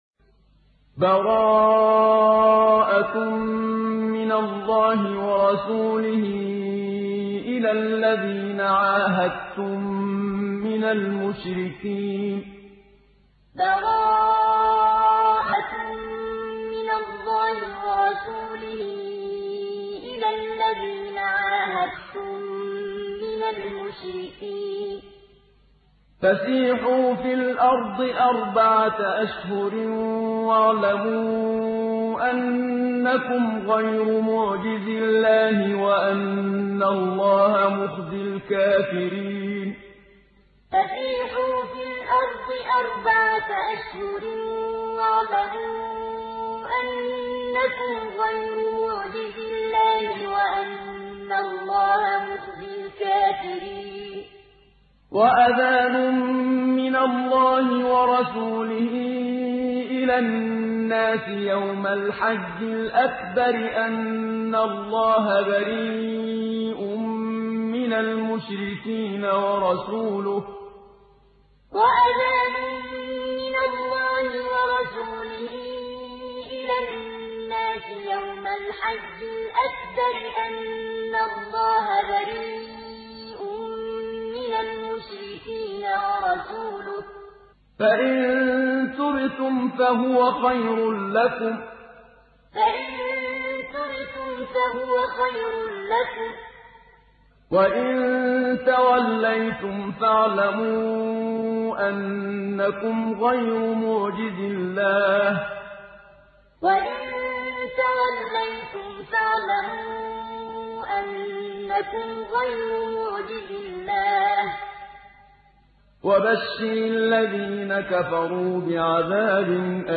Surat At Tawbah Download mp3 Muhammad Siddiq Minshawi Muallim Riwayat Hafs dari Asim, Download Quran dan mendengarkan mp3 tautan langsung penuh
Download Surat At Tawbah Muhammad Siddiq Minshawi Muallim